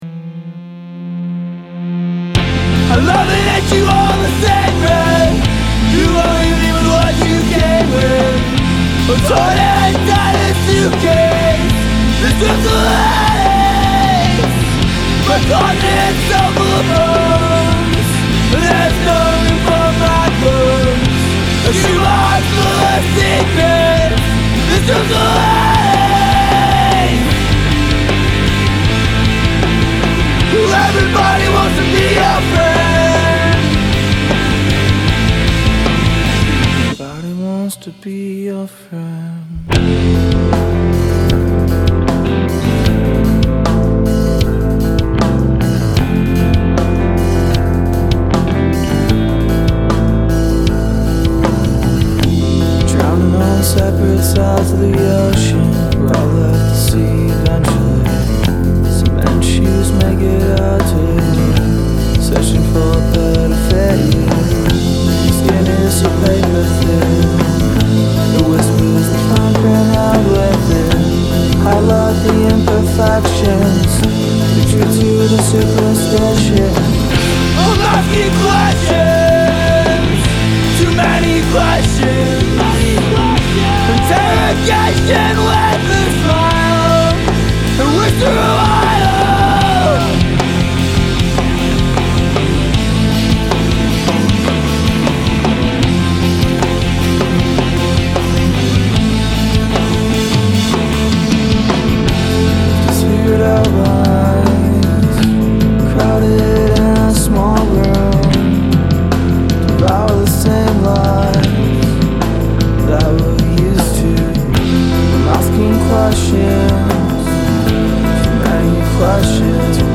Rock & Roll
Indy